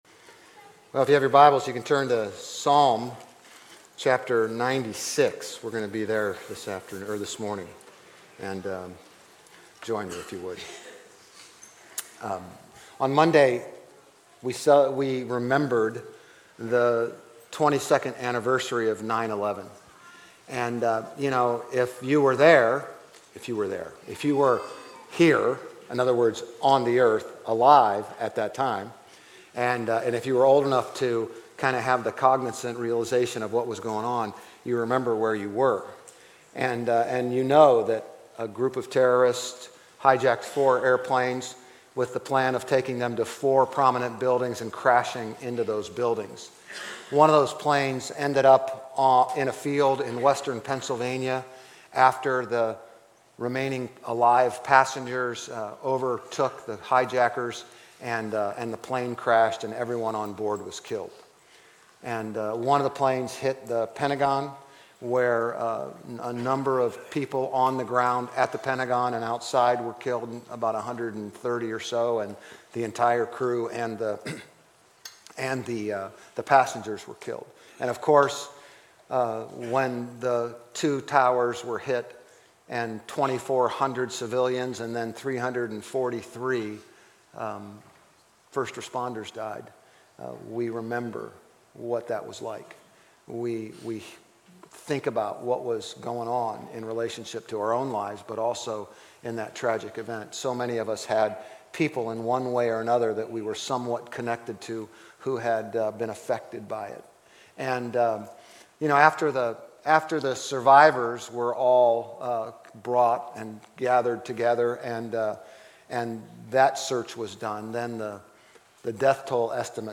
GCC-OJ-September-17-Sermon.mp3